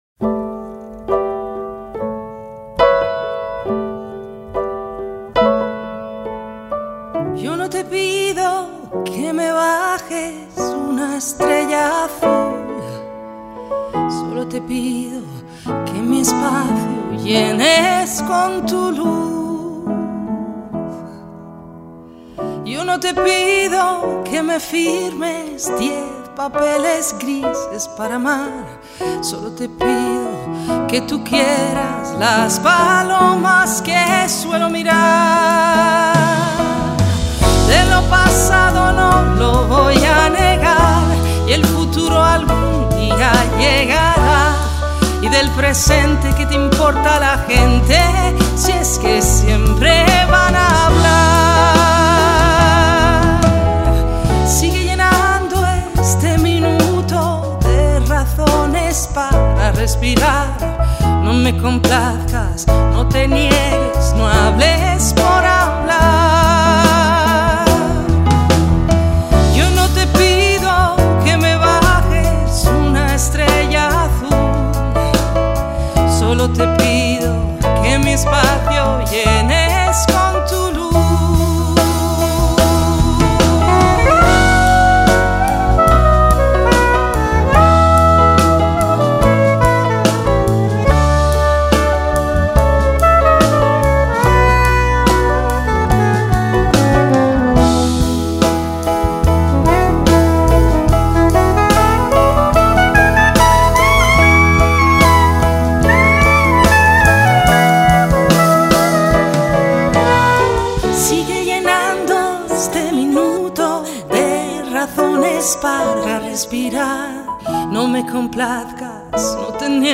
Жанр: Alternativa.